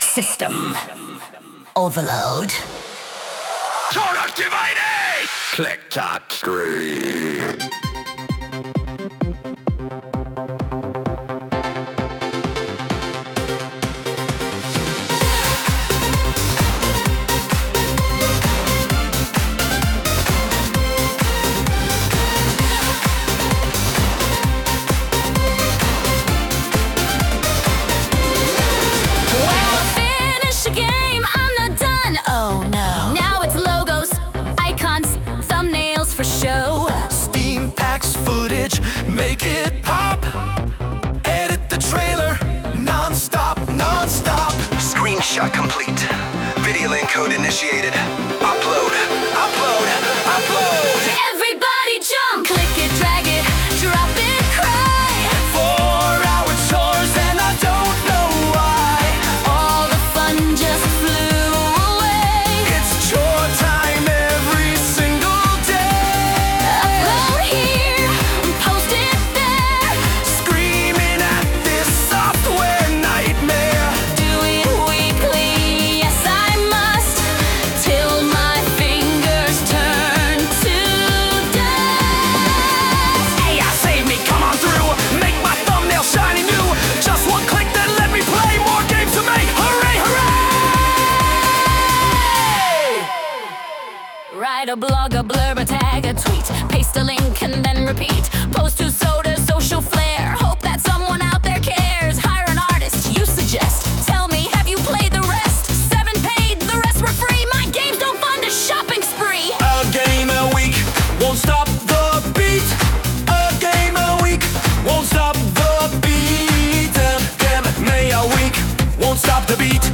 I copy+pasted everything from the "The End Bit" section of the above blog, popped it into the AI, and asked it to turn it into a 90s Europop Dance Track.
Sung by Suno